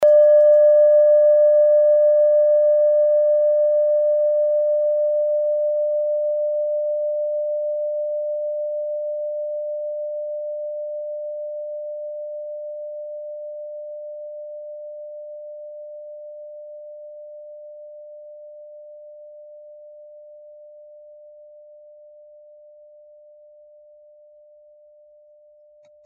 Kleine Klangschale Nr.12
Sie ist neu und ist gezielt nach altem 7-Metalle-Rezept in Handarbeit gezogen und gehämmert worden.
(Ermittelt mit dem Minifilzklöppel)
Der Saturnton liegt bei 147,85 Hz und ist die 37. Oktave der Umlauffrequenz des Saturns um die Sonne. In unserer Tonleiter liegt dieser Ton nahe beim "D".
kleine-klangschale-12.mp3